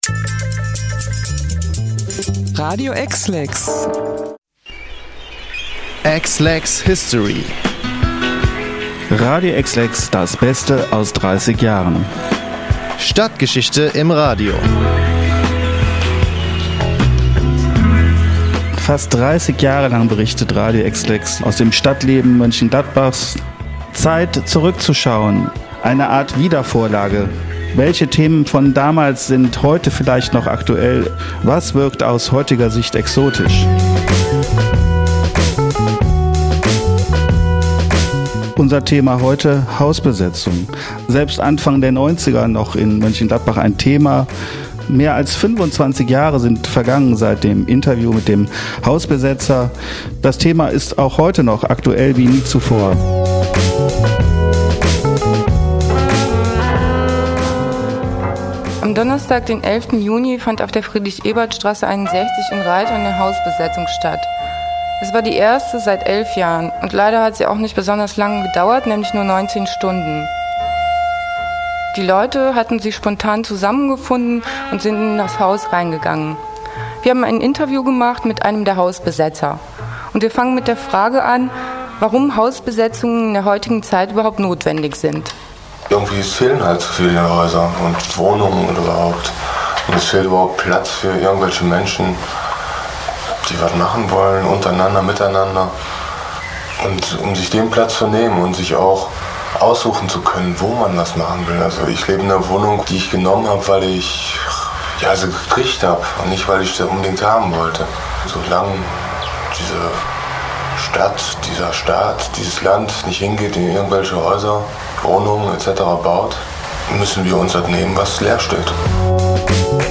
Hört euch das Interview an und entscheidet selbst, ob das Thema „Hausbesetzung“ auch heute noch aktuell ist!